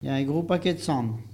Locutions vernaculaires